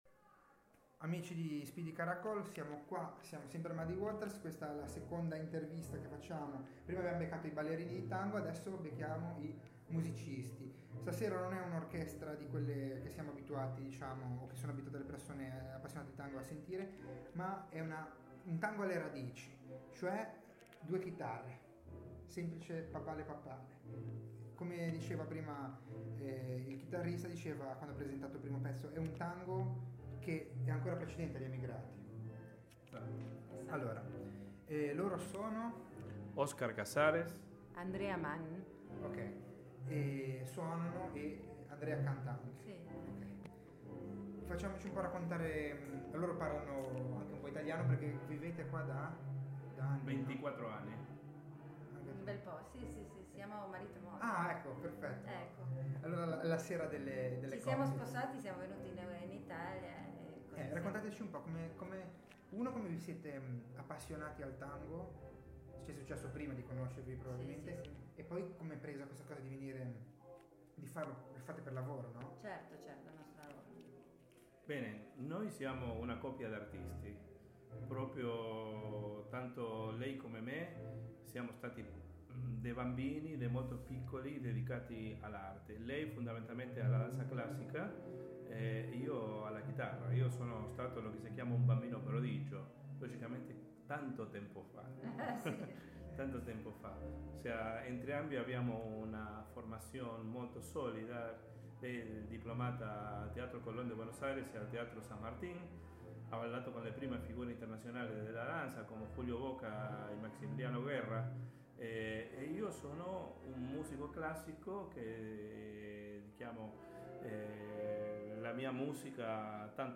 intervista
Lo show si è tenuto al mitico Muddy Waters, e l'invervista ha avuto luogo nello stesso camerino in cui abbiamo intervistato Ian Paice non molto tempo fa.